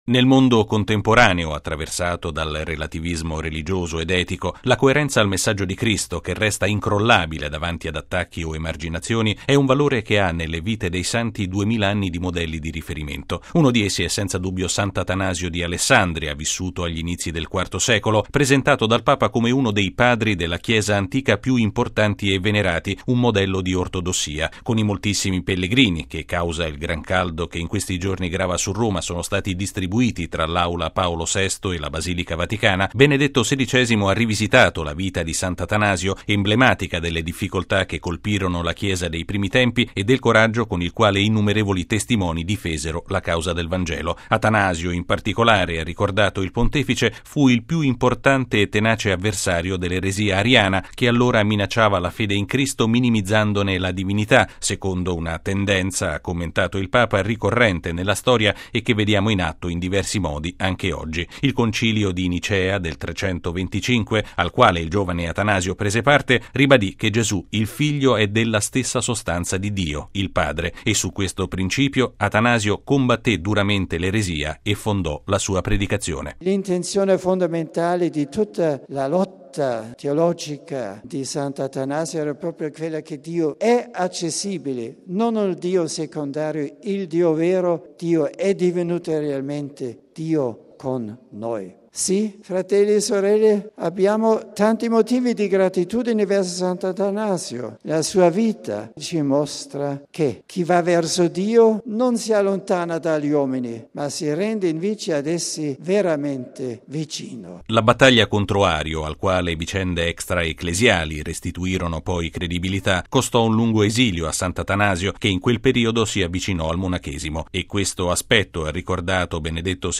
◊   Doppio saluto, questa mattina, per Benedetto XVI alle decine di migliaia di pellegrini di cinque continenti radunatisi in Vaticano per l’udienza generale.